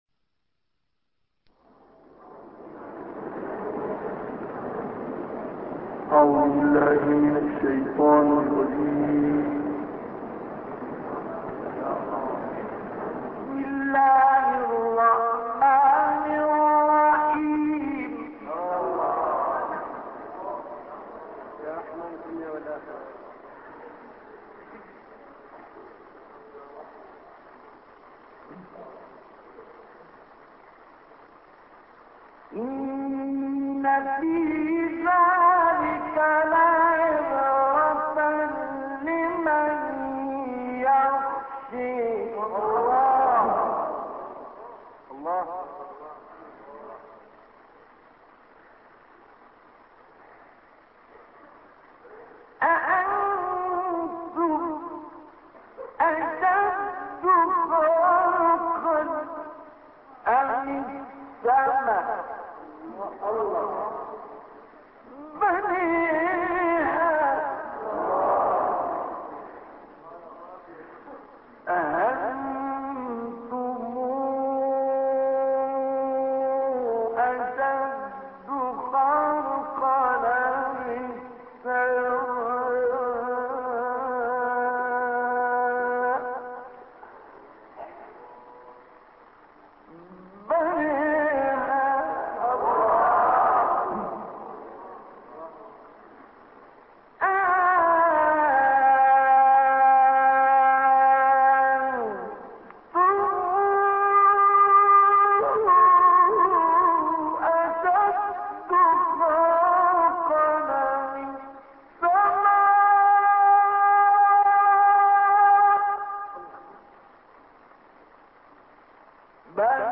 سورة النازعات ـ مصطفى اسماعيل ـ رست وعجم - لحفظ الملف في مجلد خاص اضغط بالزر الأيمن هنا ثم اختر (حفظ الهدف باسم - Save Target As) واختر المكان المناسب